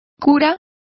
Complete with pronunciation of the translation of clergyman.